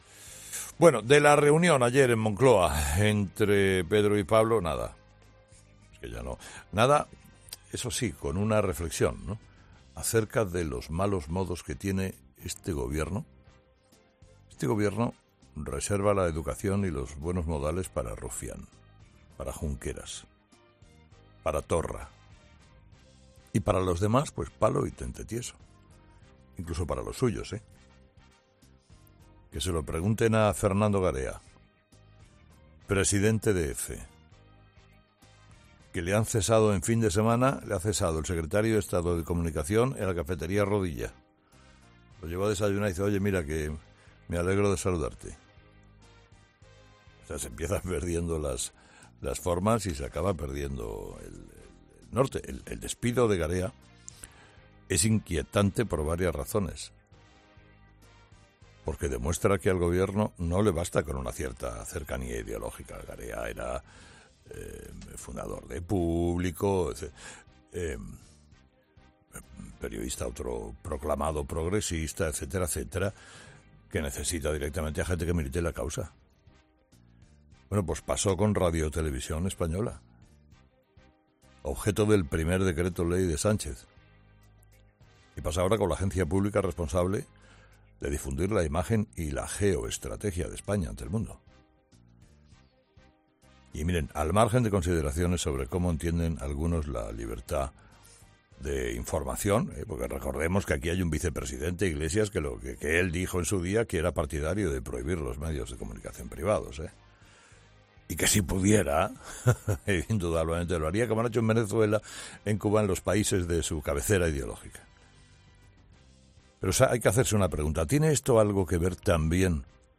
En su monólogo de este martes, Carlos Herrera ha alertado del grave precedente que sienta esta destitución.